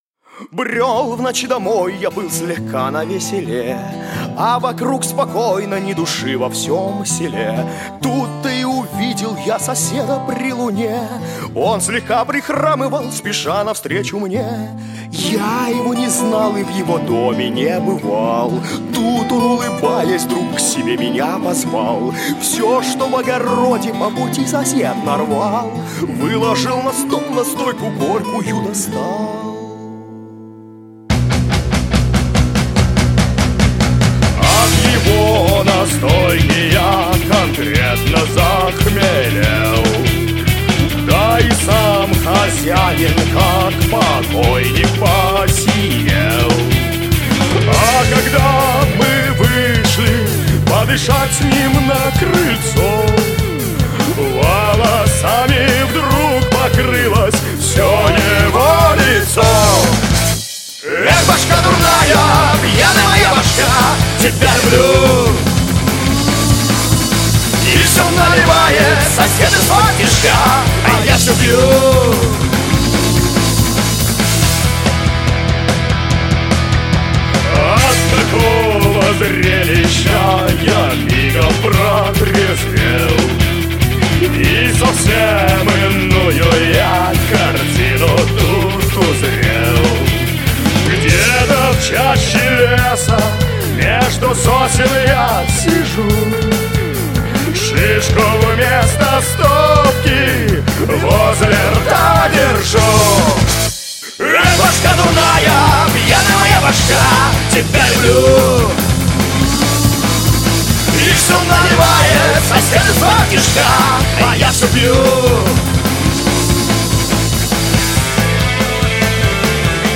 Жанр: Жанры / Рок